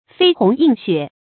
飛鴻印雪 注音： ㄈㄟ ㄏㄨㄥˊ ㄧㄣˋ ㄒㄩㄝˇ 讀音讀法： 意思解釋： 比喻事情經過所留下的痕跡。